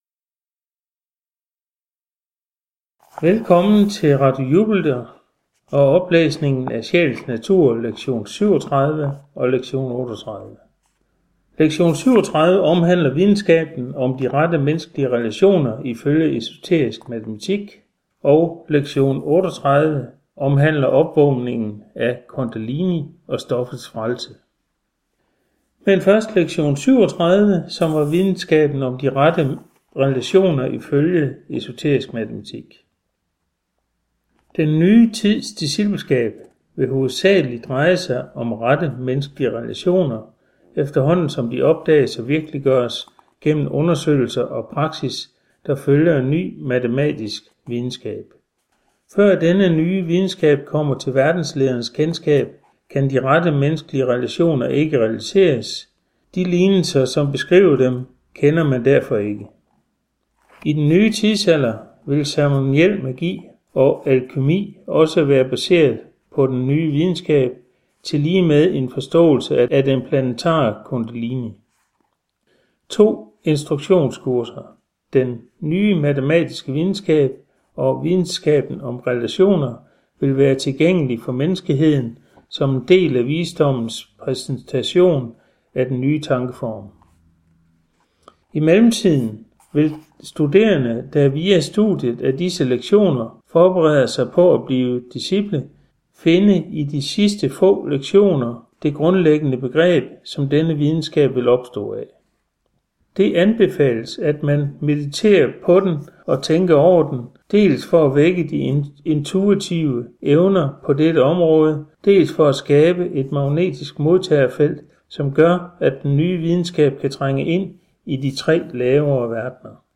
Sjælens Natur Lektion 37 & 38 oplæst